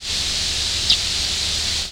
McCown's Longspur nocturnal
presumed McCown's Longspur nocturnal flight calls